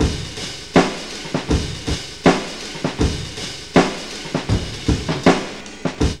JAZZLP3 80.wav